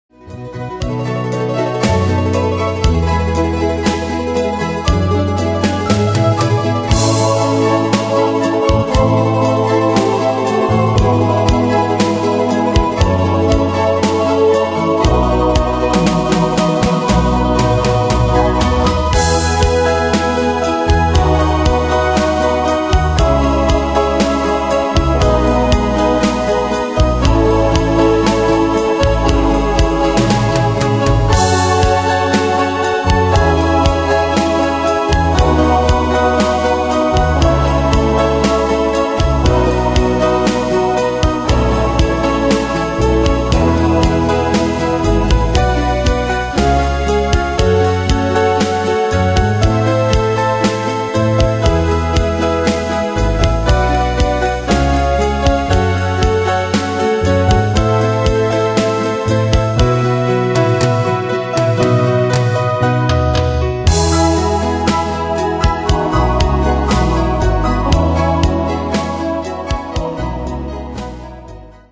Demos Midi Audio
Danse/Dance: Ballade Cat Id.
Pro Backing Tracks